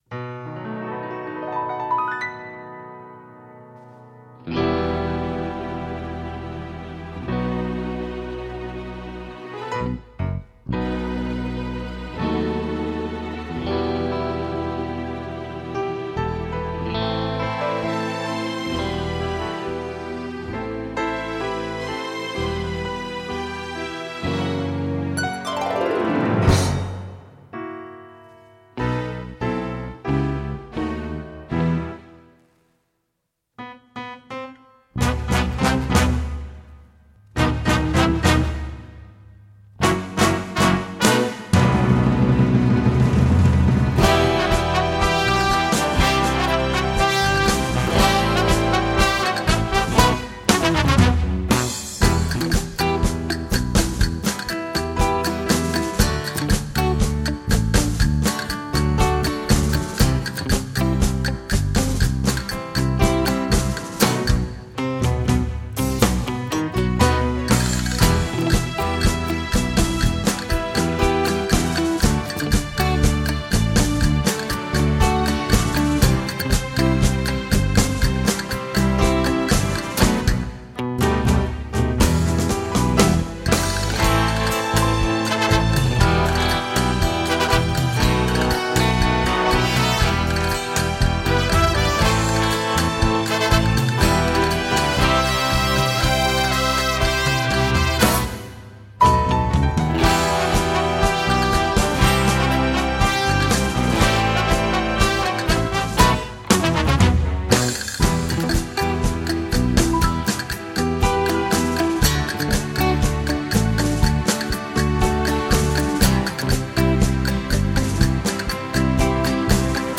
Without singing: